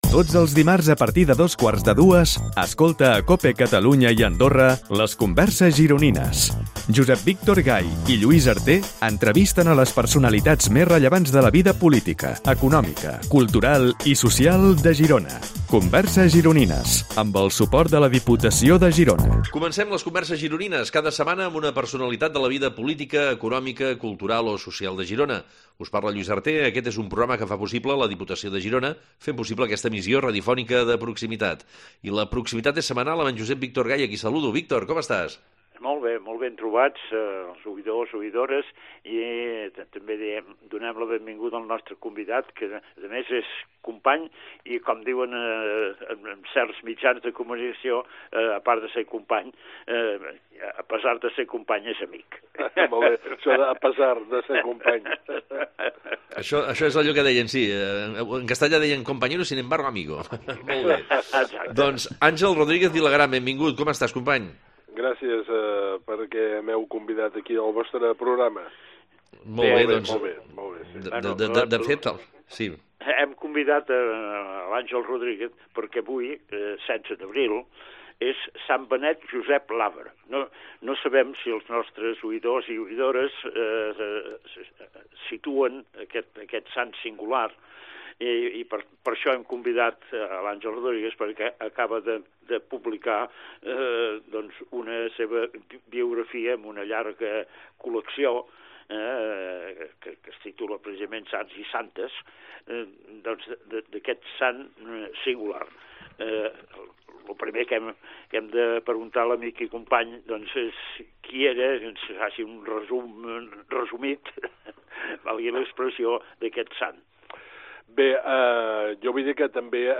A “Converses Gironines” entrevistem algunes de les grans personalitats rellevants de la vida política, econòmica cultural o social de Girona.
Aquestes converses es creen en un format de tertúlia en el que en un clima distès i relaxat els convidats ens sorprenen pels seus coneixements i pel relat de les seves trajectòries.